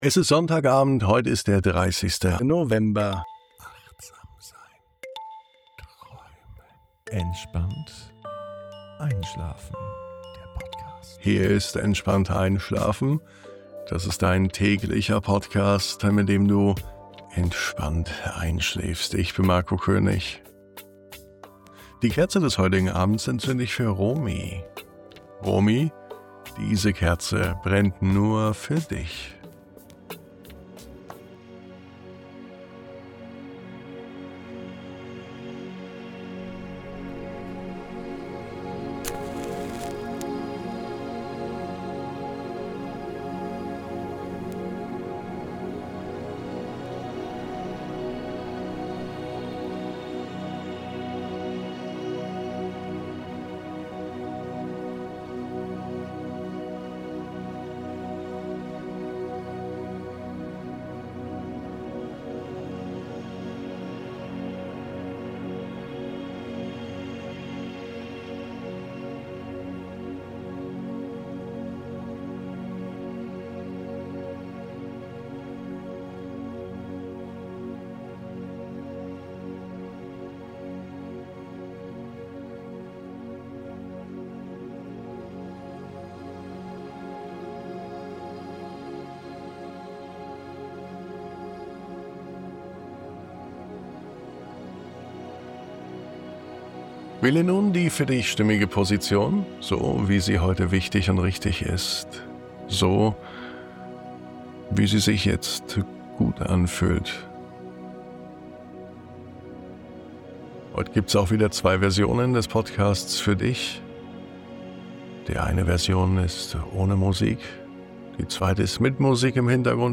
Diese Traumreise führt dich auf eine weiche Wiese unter einem klaren Sternenzelt. Du liegst ganz ruhig, spürst den warmen Boden unter dir – und über dir öffnet sich ein Universum aus Stille, Licht und Geborgenheit.